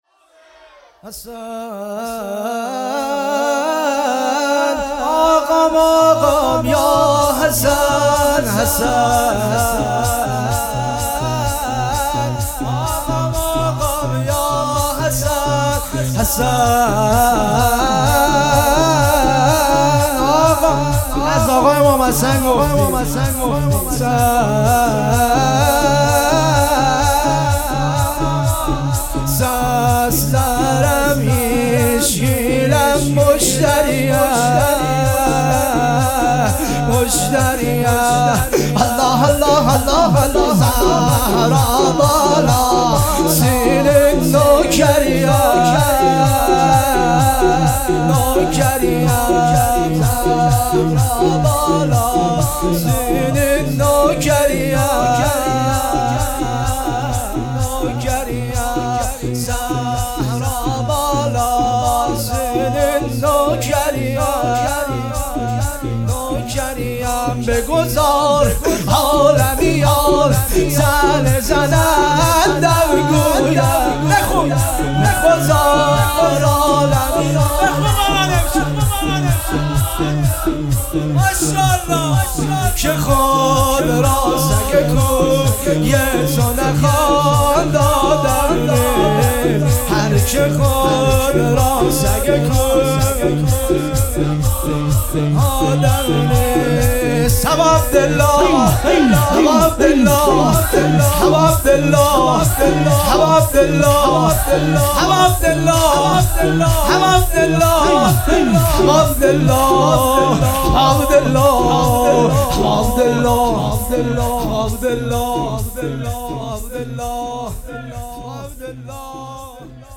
مراسم هفتگی شهادت حضرت رباب (س)۱۴-۱۲-۹۹